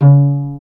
Index of /90_sSampleCDs/Roland LCDP13 String Sections/STR_Vcs Marc&Piz/STR_Vcs Pz.3 dry